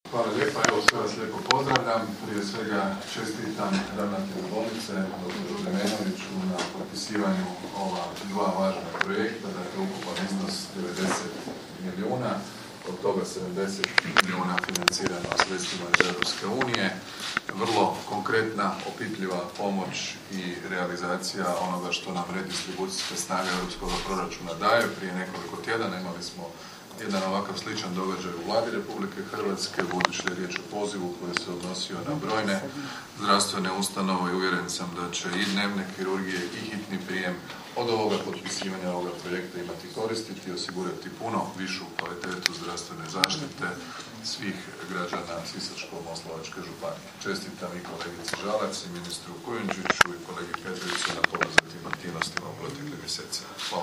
Predsjednik Vlade RH Andrej Plenković: